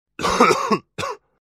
cough1.wav